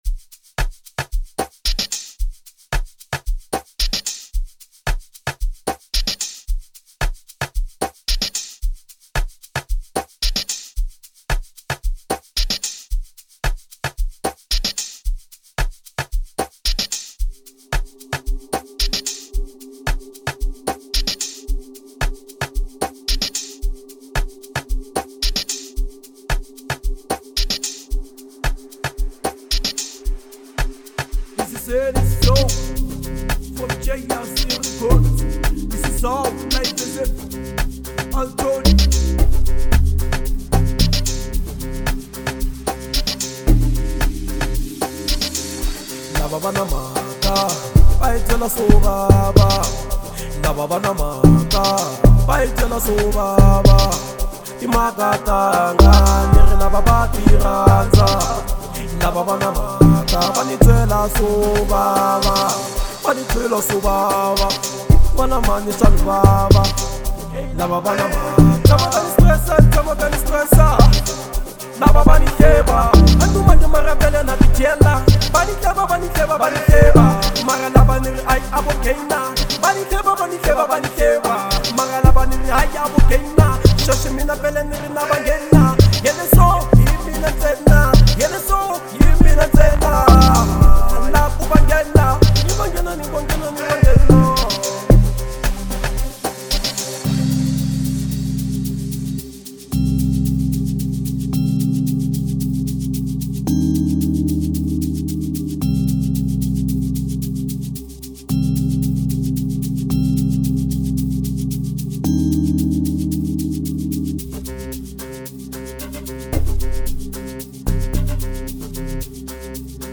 04:11 Genre : Amapiano Size